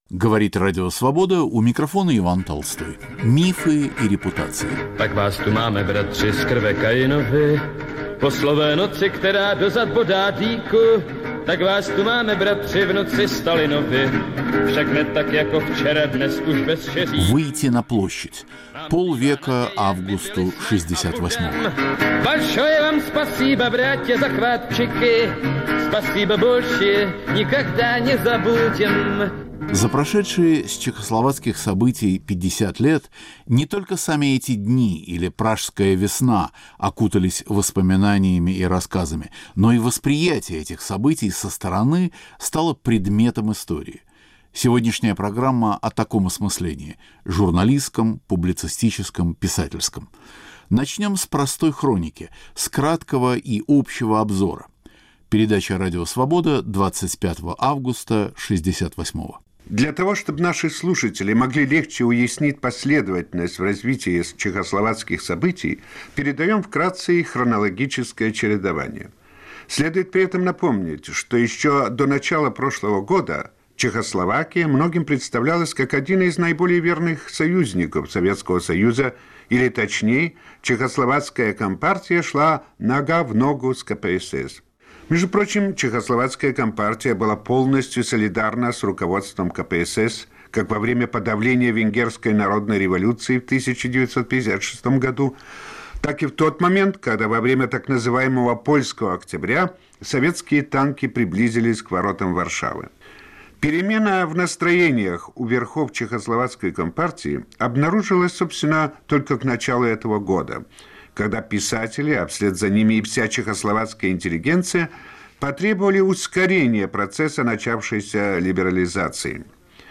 К 50-летию оккупации Чехословакии и протеста на Красной площади. Передача построена на архивных записях Радио Свобода: голоса дикторов тех дней, выступления писателей и публицистов.